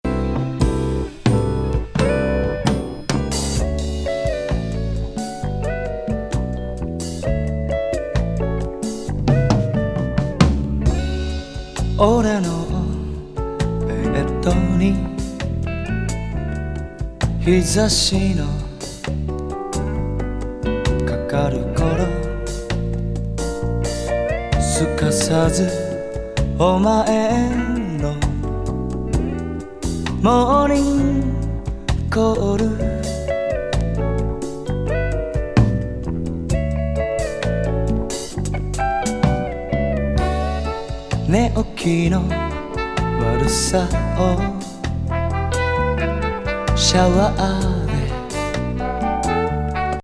トロットロ極上マドロミ・メロウ・ソウル